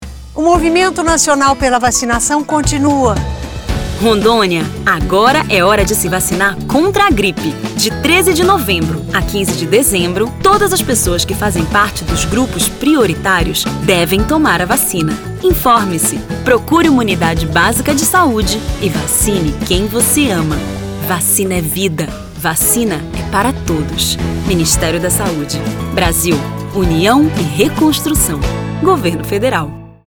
Rondônia: Spot - Vacinação Contra a Gripe em Rondônia - 30seg .mp3